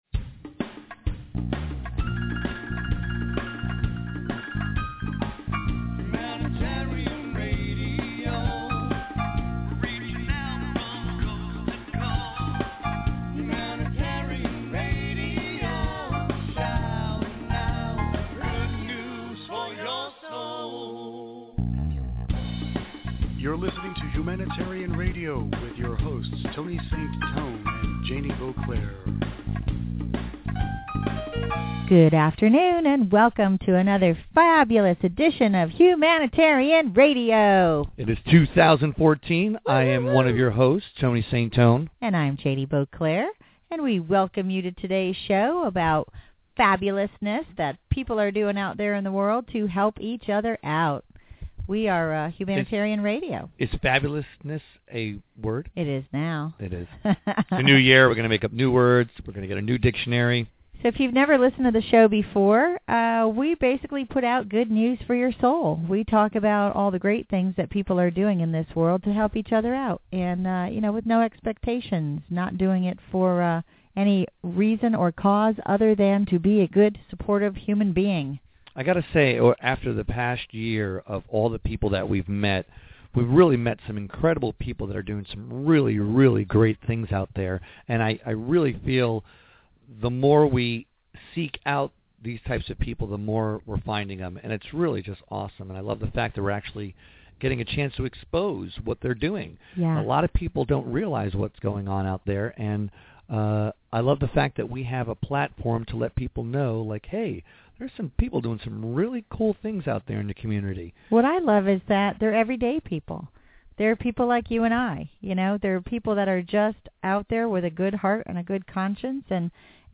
Radio interview